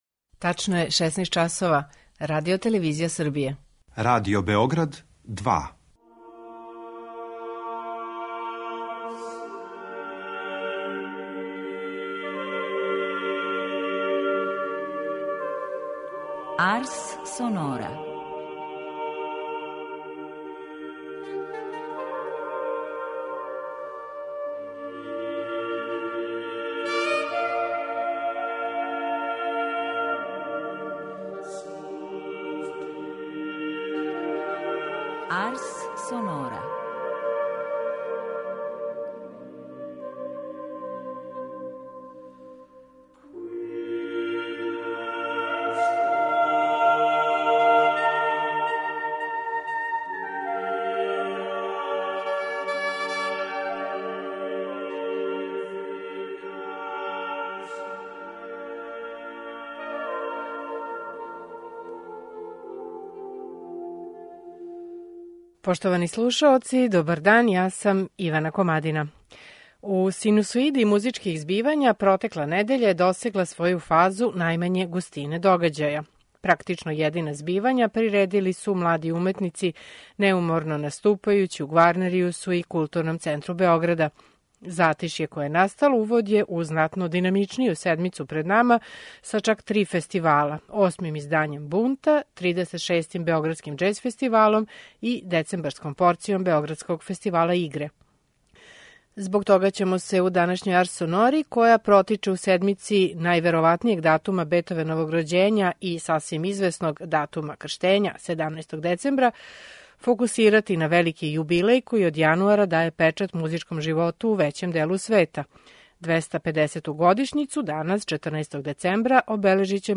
Лудвиг ван Бетовен и његов велики јубилеј и даље су у фокусу, а из ауторовог стваралаштва поново издвајамо његове гудачке квартете. У интерпретацији квартета Julliard слушаћете квартет број 14, опус 131 у цис-моллу.